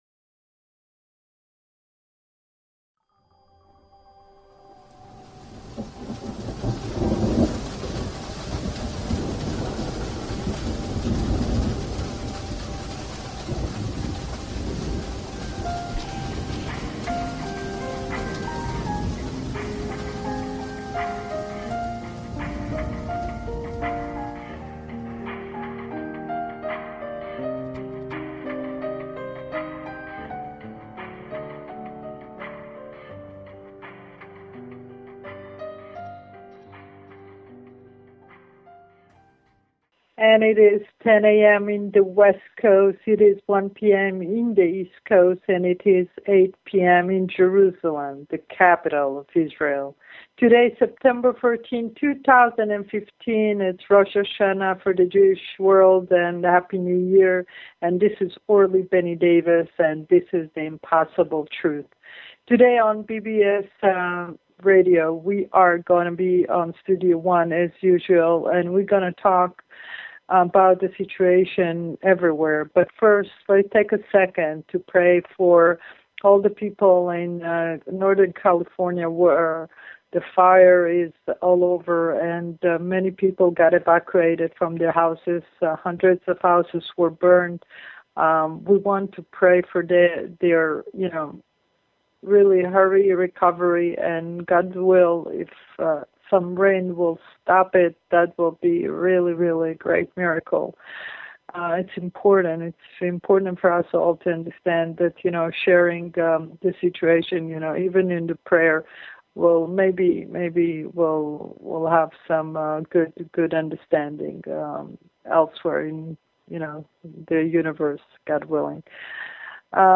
The Impossible Truth on BBS Radio.